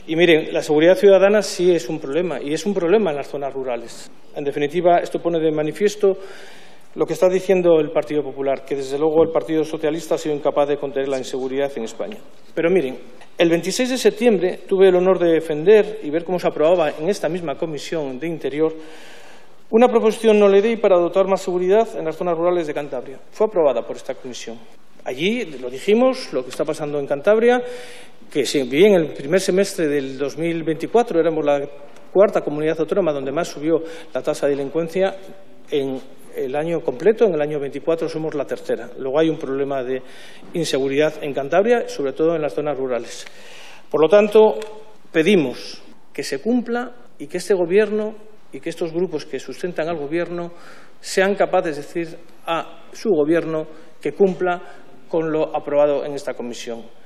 El diputado por Cantabria del Partido Popular, Félix de las Cuevas, volvió a defender hoy en la Comisión de Interior del Congreso la necesidad de reforzar la presencia de la Guardia Civil en las zonas rurales.
Félix de las Cuevas se expresó así durante el debate de una PNL presentada por Vox, señalando que, tanto la propuesta que presentó su grupo como la de Vox, coinciden en aspectos fundamentales, como el aumento de efectivos de la Guardia Civil, la reversión del cierre de cuarteles y la mejora de los recursos destinados a esta institución.